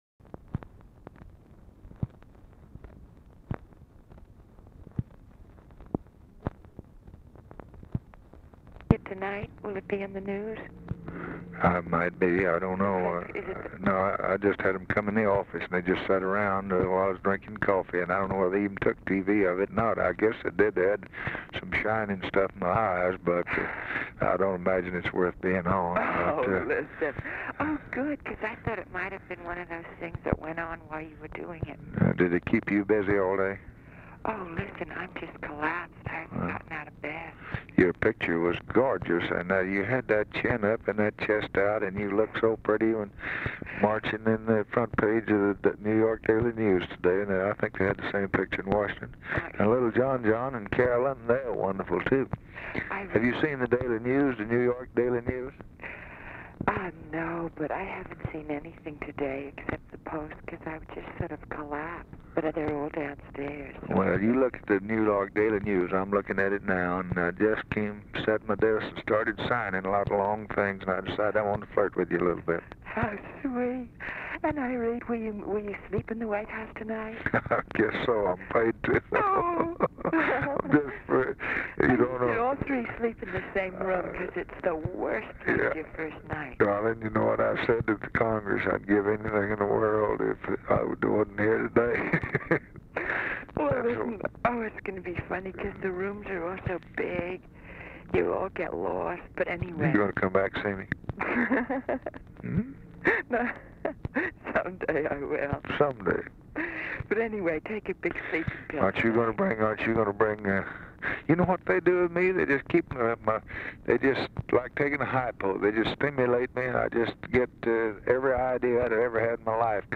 Oval Office or unknown location
"#2"; "(TRANS)"; RECORDING STARTS AFTER CONVERSATION HAS BEGUN; FORMERLY CLOSED-DONOR'S DEED OF GIFT (C); PREVIOUSLY OPENED 09/1998
Telephone conversation
Dictation belt